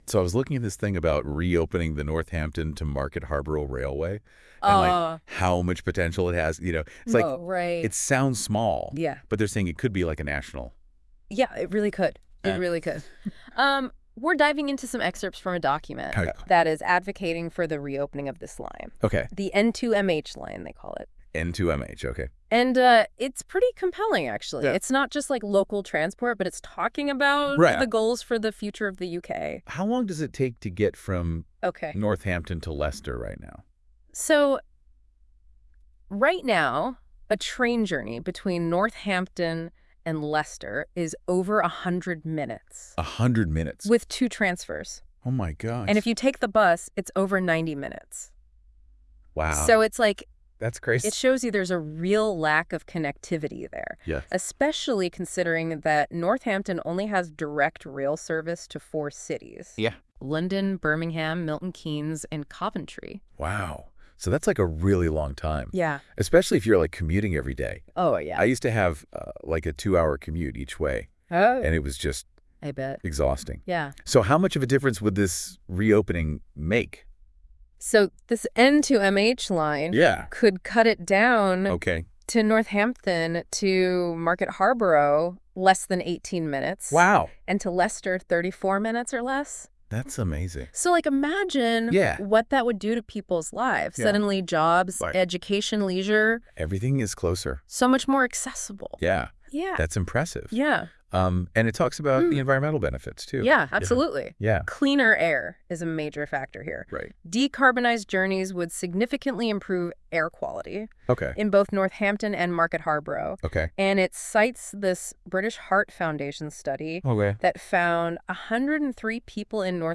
We asked NotebookLM to make a podcast about N2MH. Listen to two AI hosts taking a  “deep dive” into N2MH here!